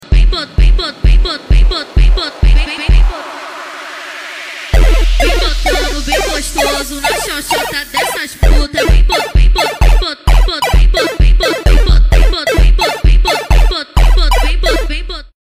okay..- animation failed. (bad quality)